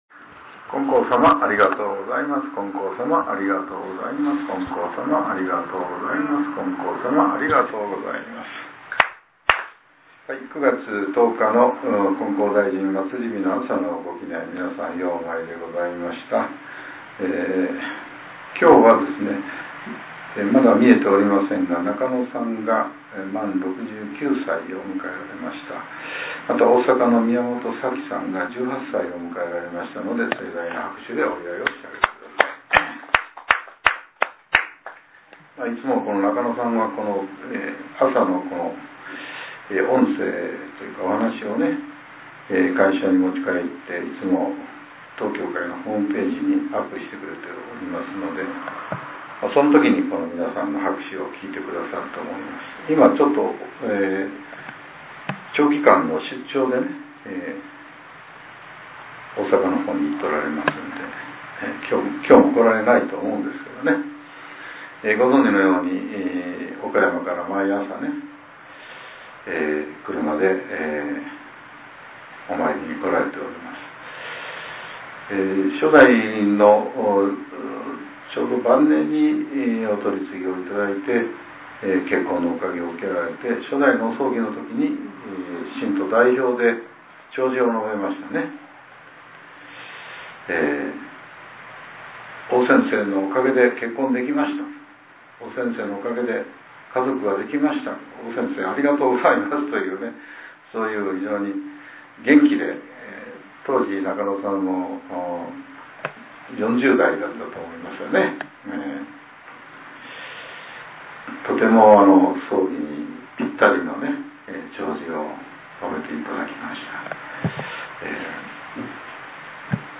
令和７年９月１０日（朝）のお話が、音声ブログとして更新させれています。